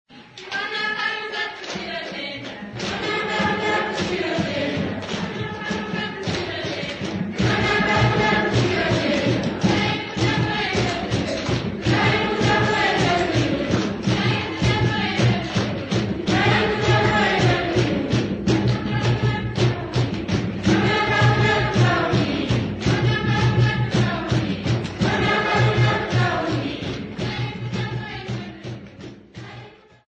Folk music
Field recordings
Africa Nambia Oshikuku sx
Choral church music accompanied by drums and clapping